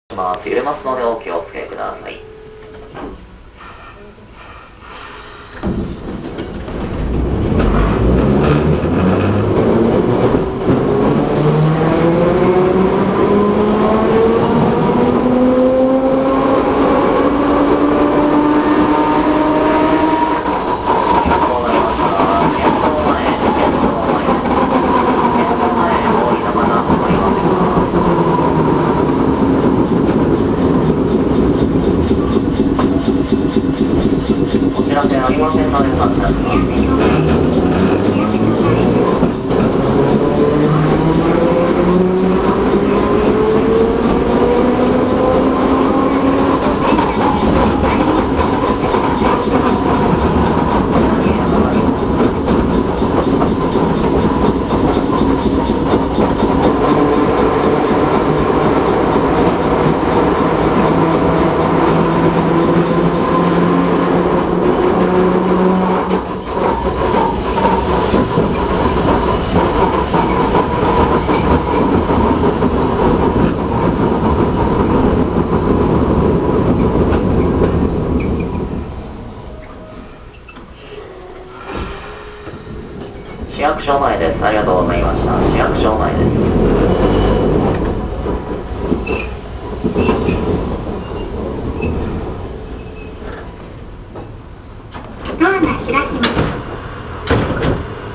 ・50形走行音
【城南線】大街道→県庁前A→市役所前（1分38秒：534KB）…78号にて
一応前・中・後期で分けたのですが基本的に音は同じで、全て吊り掛け式。個人的には、数ある路面電車の中でもかなり派手な音を出す部類に感じました。